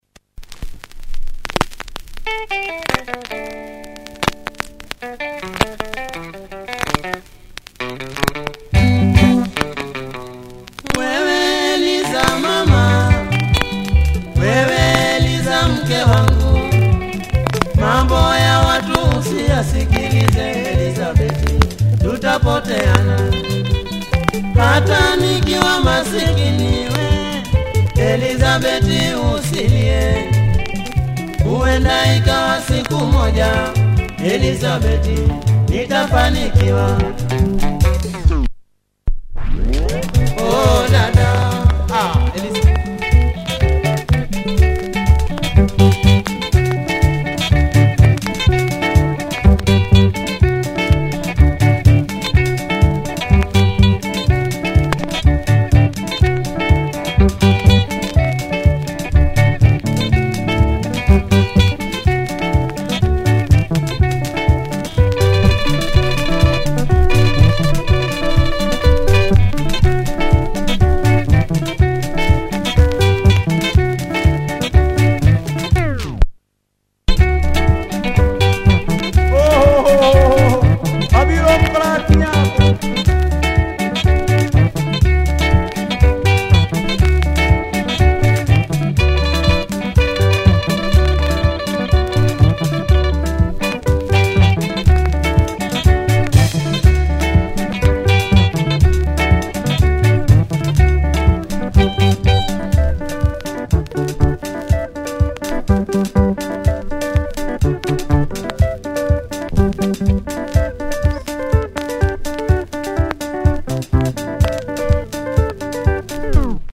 Famous group, great harmonics!
Nasty scratch in the start hence the price.